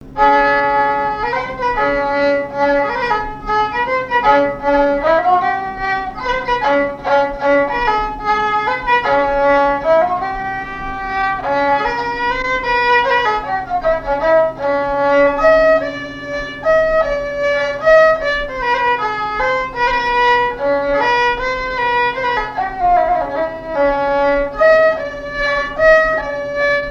danse : mazurka
Répertoire de marches de noce et de danse
Pièce musicale inédite